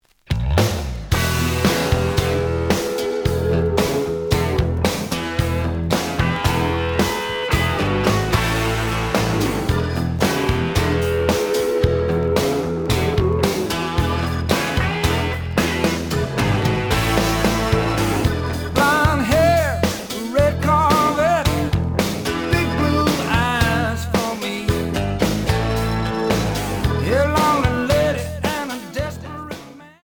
The audio sample is recorded from the actual item.
●Format: 7 inch
●Genre: Folk / Country